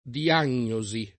[ di- # n’n’o @ i ]